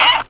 R - Foley 170.wav